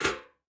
bucketputdown.ogg